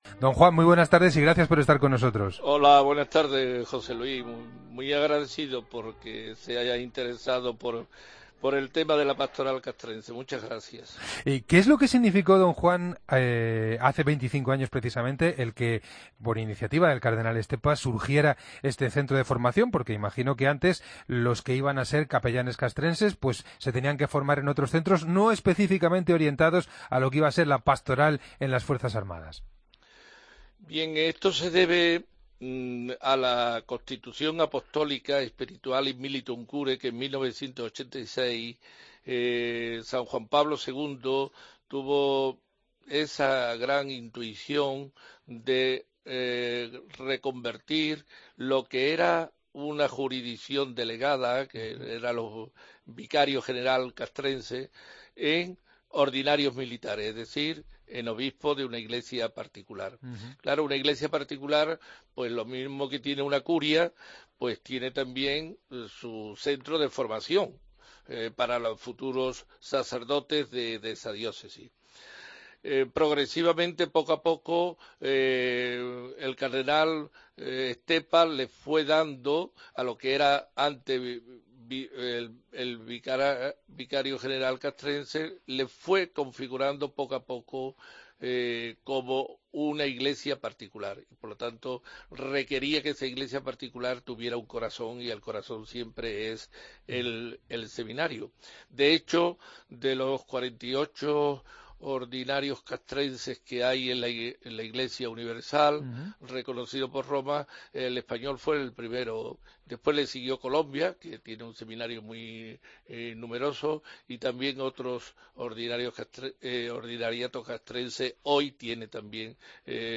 Entrevista al arzobispo castrense con motivo del 25 aniversario del Seminario Castrense “Juan Pablo II”